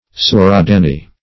Search Result for " suradanni" : The Collaborative International Dictionary of English v.0.48: Suradanni \Su`ra*dan"ni\, n. A valuable kind of wood obtained on the shores of the Demerara River in South America, much used for timbers, rails, naves and fellies of wheels, and the like.